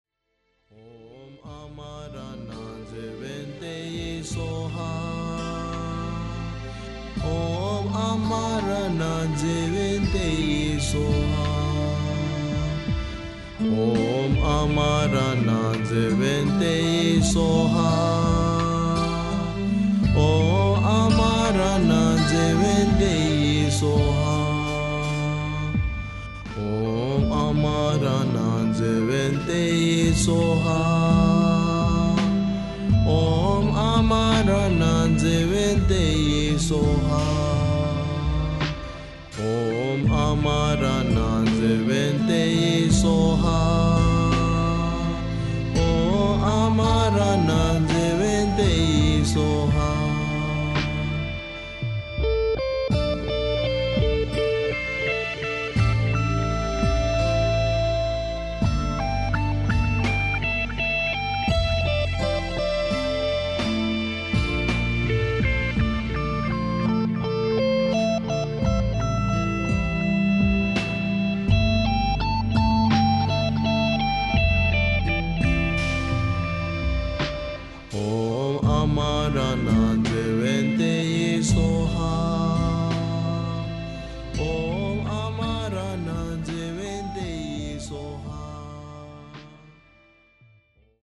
长寿佛经 诵经 长寿佛经--未知 点我： 标签: 佛音 诵经 佛教音乐 返回列表 上一篇： 地藏经 下一篇： 地藏菩萨本愿功德经 相关文章 职场32意和同悦--佛音大家唱 职场32意和同悦--佛音大家唱...